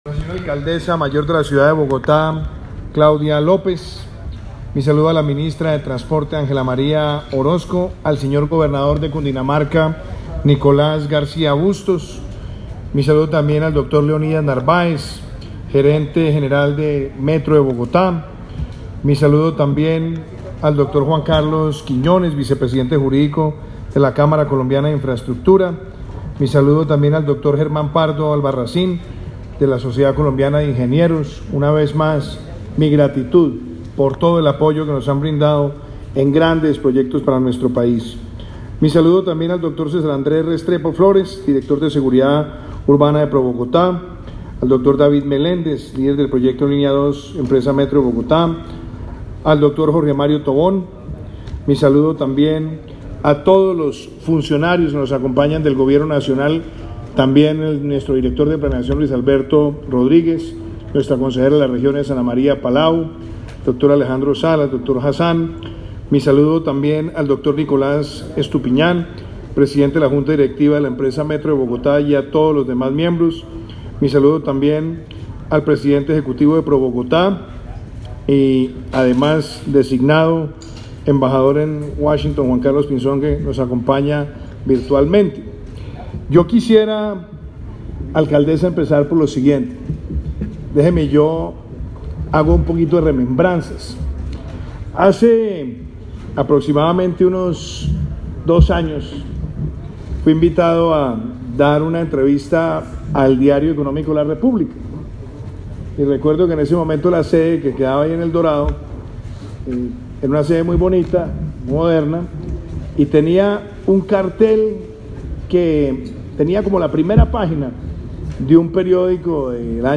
El presidente de la República, Iván Duque, la ministra de Transporte, Ángela María Orozco, y la alcaldesa de Bogotá, Claudia López, participaron en Fontanar del Río, extremo occidental de la localidad de Suba, de la ratificación de los gobiernos nacional y distrital de la financiación y estructuración de la Línea 2 del Metro de Bogotá.